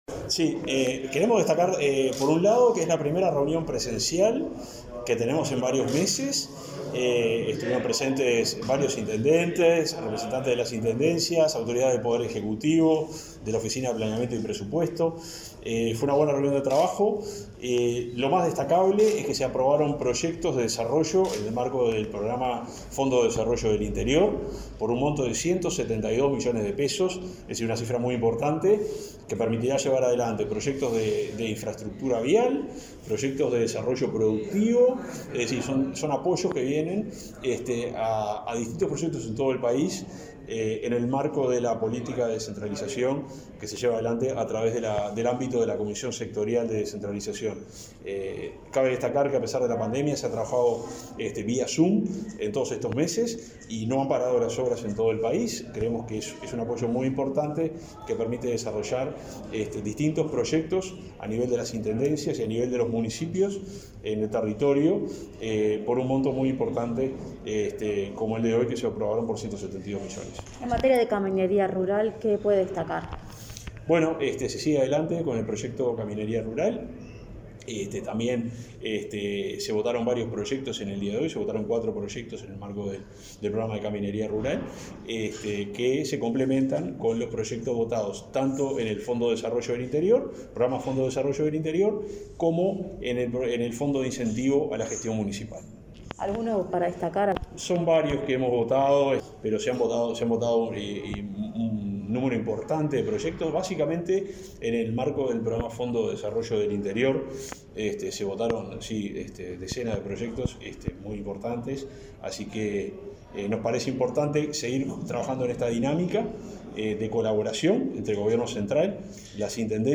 Entrevista al subsecretario de Transporte, Juan José Olaizola